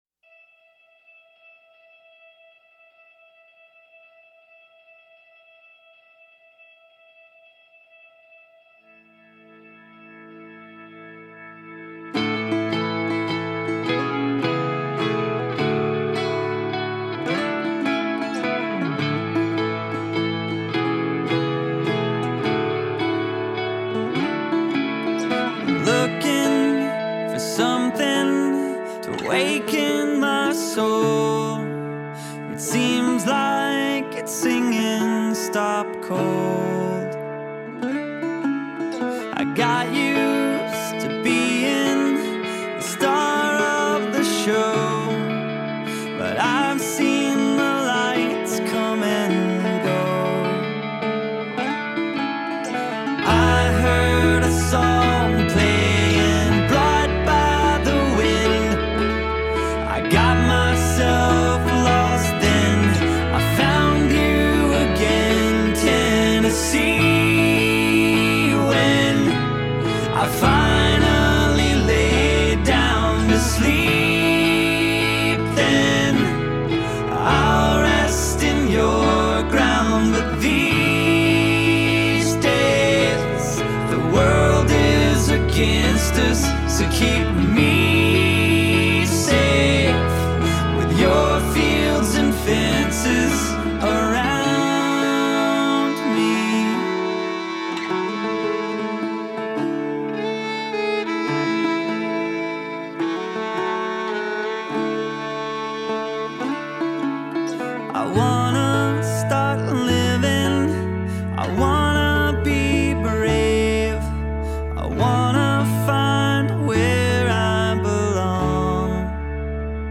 pop-punk